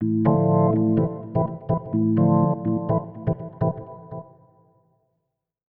ORGAN003_VOCAL_125_A_SC3(R).wav